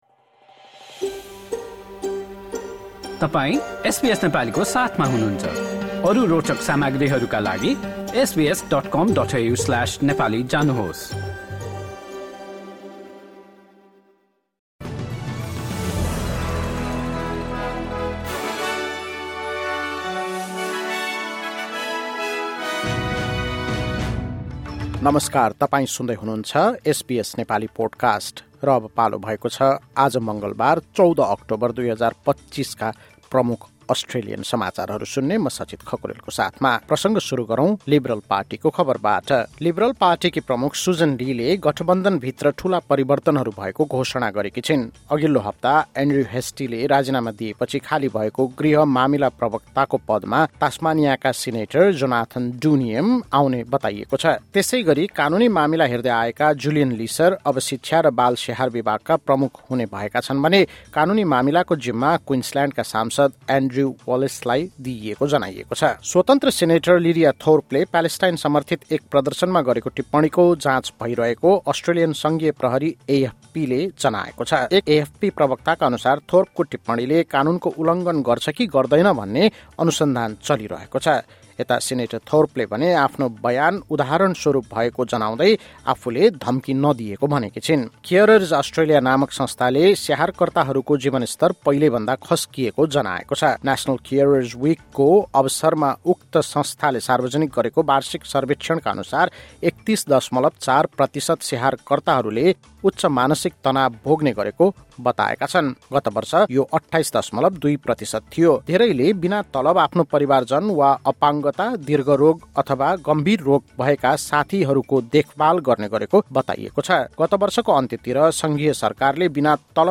एसबीएस नेपाली प्रमुख अस्ट्रेलियन समाचार: मङ्गलवार, १४ अक्टोबर २०२५